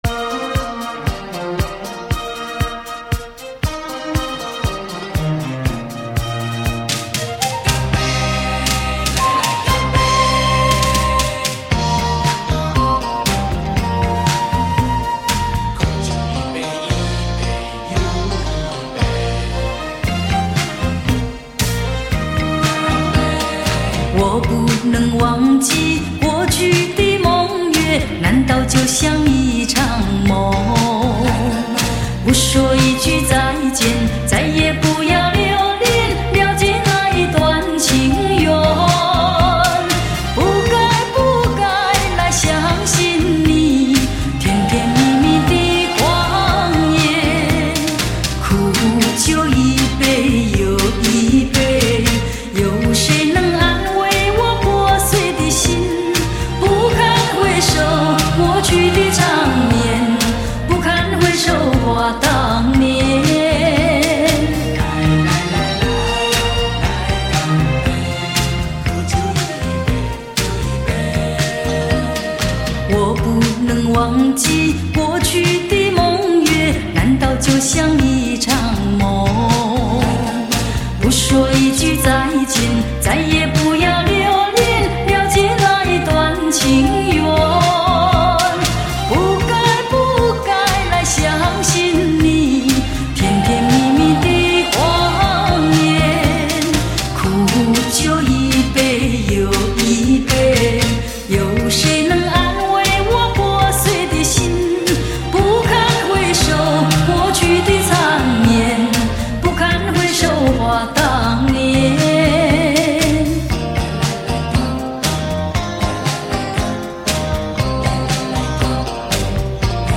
3D音效+环绕360°
复古黑胶
德国黑胶母带后期处理技术